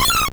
Cri de Mélo dans Pokémon Or et Argent.